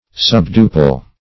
Meaning of subduple. subduple synonyms, pronunciation, spelling and more from Free Dictionary.
Search Result for " subduple" : The Collaborative International Dictionary of English v.0.48: Subduple \Sub"du*ple\, a. (Math.)